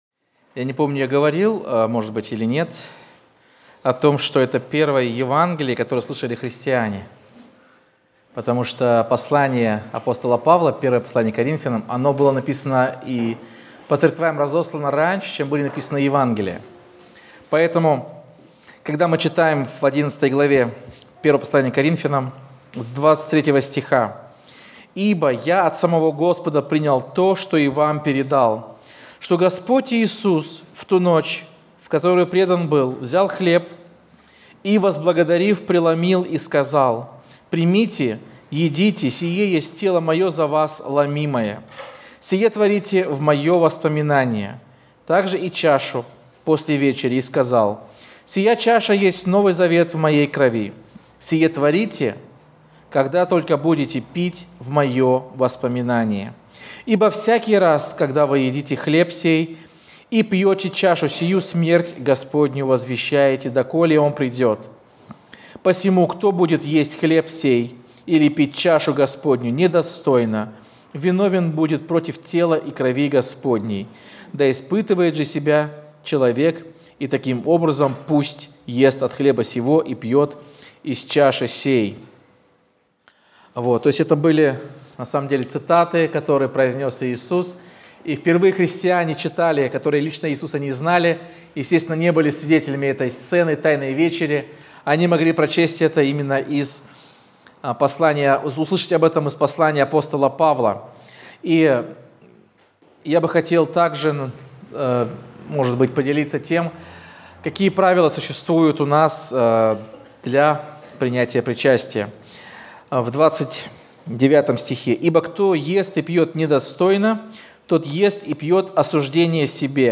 Воскресная проповедь - 2025-05-04 - Сайт церкви Преображение